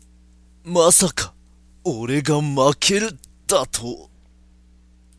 RPG戦闘終了後キャラクター台詞です。
作った当時に出していた声を忘れたので、2通り録ってみました。
しっかし、マイクの集音力高すぎです。
マウスのクリック音まで入ってますよ。